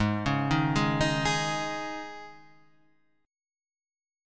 Absus4 chord